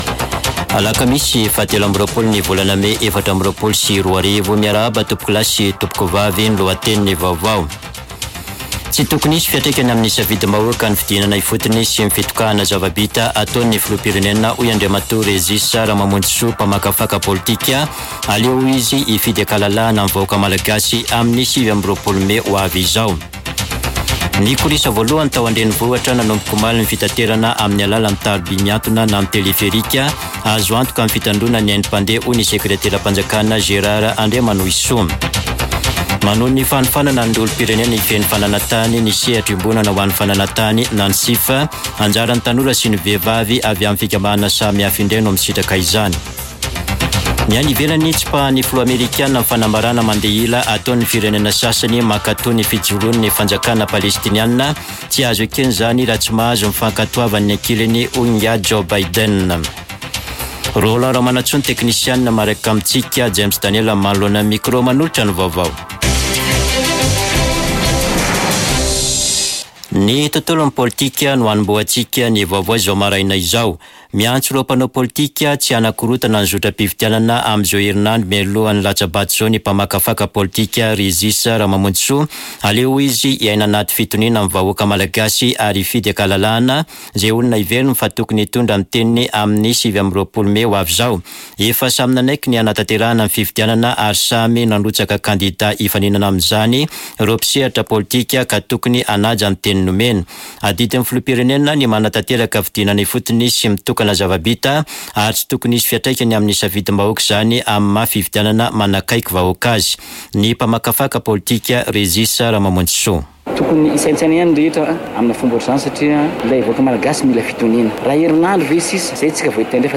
[Vaovao maraina] Alakamisy 23 mey 2024